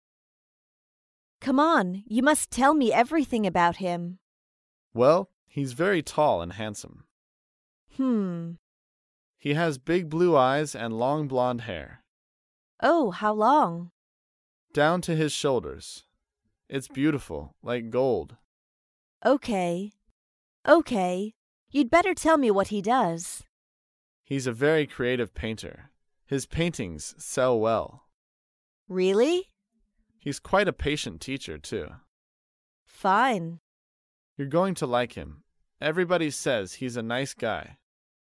在线英语听力室高频英语口语对话 第158期:了解异性的听力文件下载,《高频英语口语对话》栏目包含了日常生活中经常使用的英语情景对话，是学习英语口语，能够帮助英语爱好者在听英语对话的过程中，积累英语口语习语知识，提高英语听说水平，并通过栏目中的中英文字幕和音频MP3文件，提高英语语感。